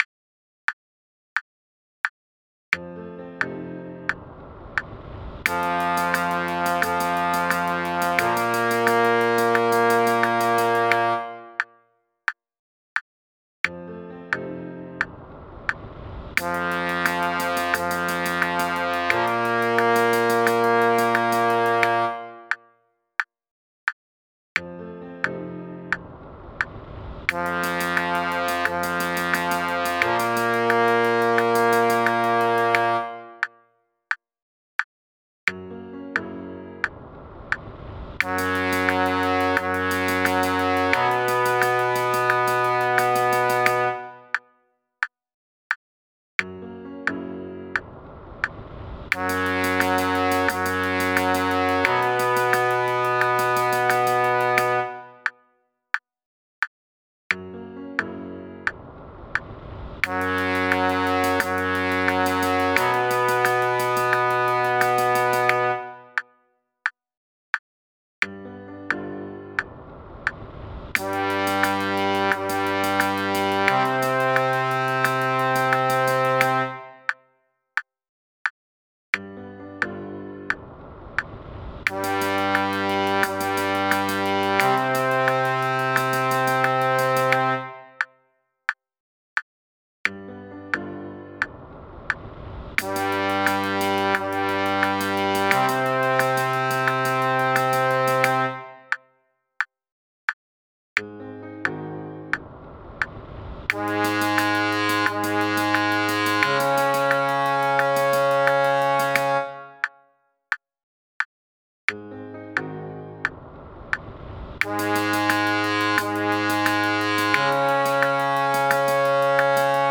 There is a very audible "wind" noise on these tracks – to indicate breathing.